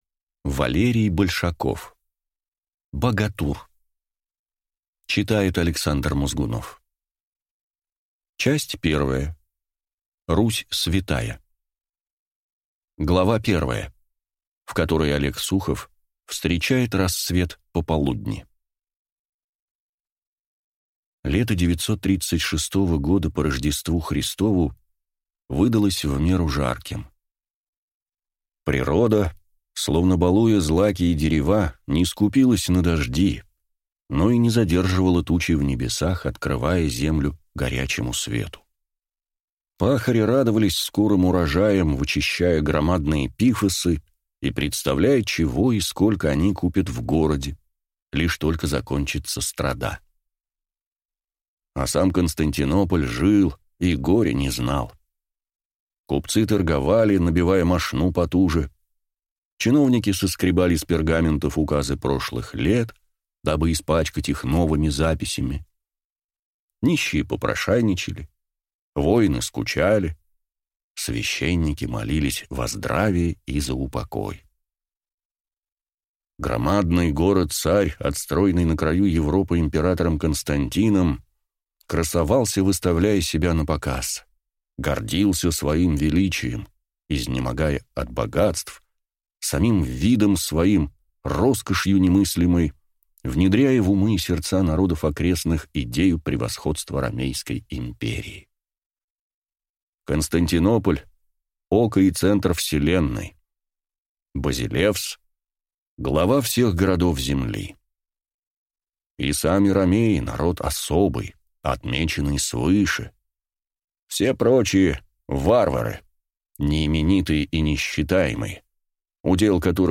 Аудиокнига Багатур | Библиотека аудиокниг
Прослушать и бесплатно скачать фрагмент аудиокниги